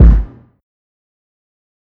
Metro Hall Kick.wav